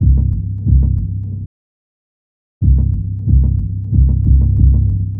Back Alley Cat (Bass 02).wav